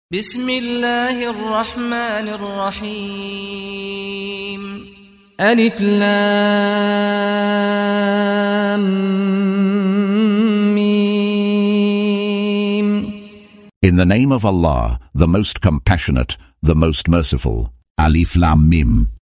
Audio version of Surah Al-Baqarah ( The Cow ) in English, split into verses, preceded by the recitation of the reciter: Saad Al-Ghamdi.